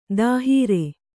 ♪ dāhīre